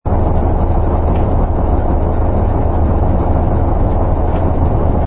engine_br_freighter_loop.wav